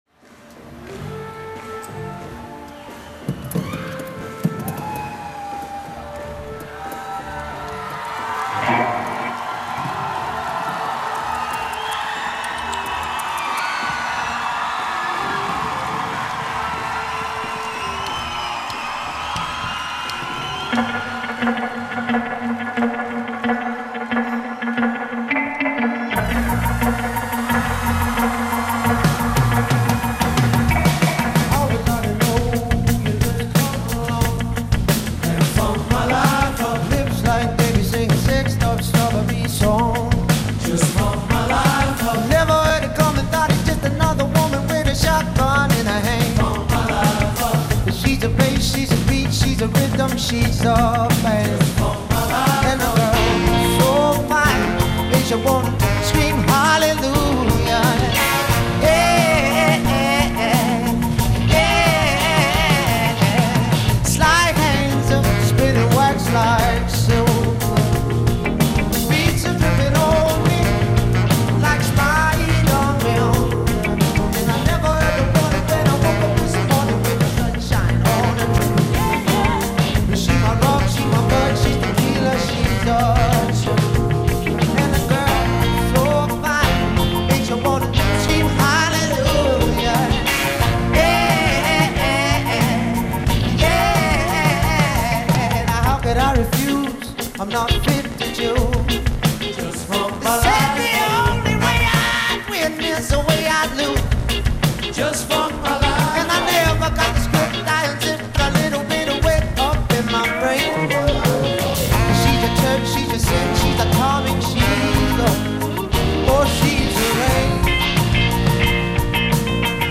It’s safe to say Festival season has begun.
who is from Glasgow and sports some amazing Soul renderings